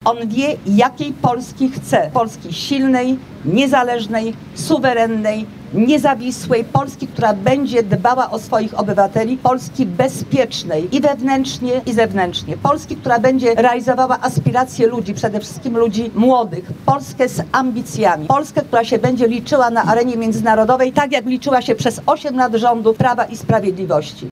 Wiceprezes Prawa i Sprawiedliwości Elżbieta Witek w Lublinie spotkała się z mieszkańcami, by udzielić poparcia dla kandydata w wyborach na prezydenta, Karola Nawrockiego i wesprzeć go w kampanii.